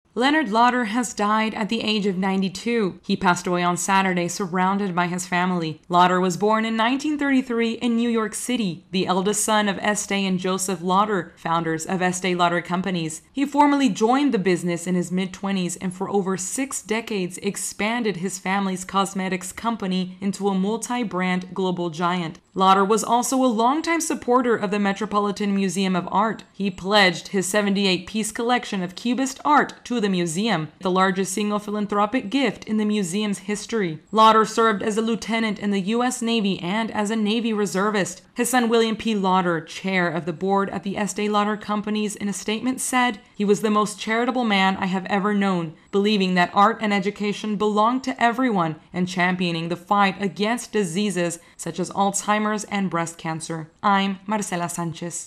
AP correspondent [Name] reports on the death of a cosmetics business giant who shaped his family's company into a global brand.